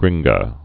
(grĭnggə)